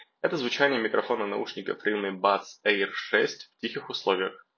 Тихая обстановка — запись в помещении с минимальным фоновым шумом.
В тихих условиях: